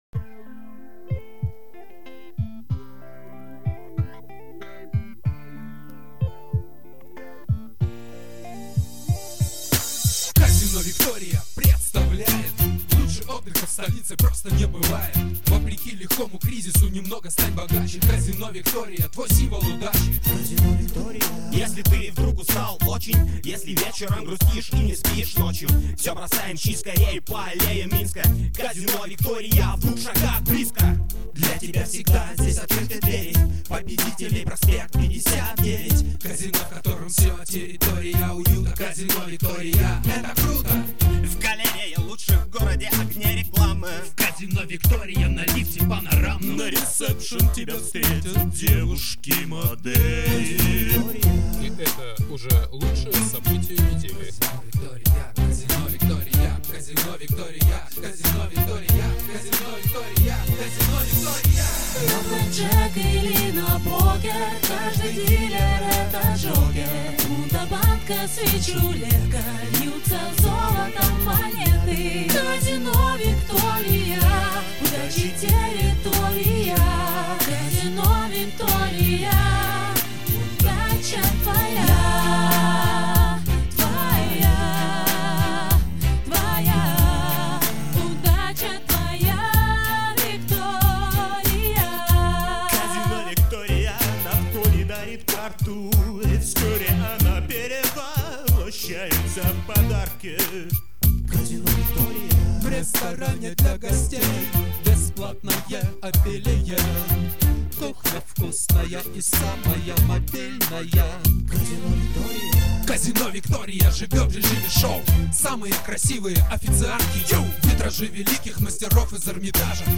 Текст кропали сами, пели сами.
Песня в стиле "Реп"а...
Очень хороший вокал.